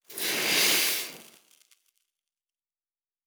Blacksmith 06.wav